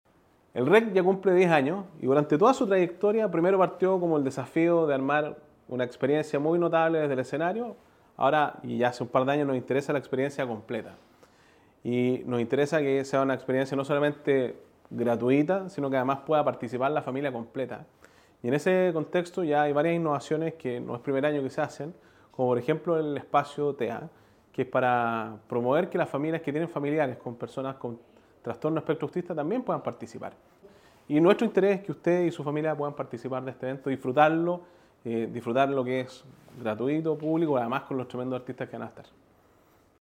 El gobernador de la región del Biobío, Sergio Giacaman, destacó los avances que el REC tiene cada año, en especial en materia de inclusión, y explicó que el objetivo es que “todas las familias puedan disfrutar de la música y la cultura en un ambiente seguro y accesible”.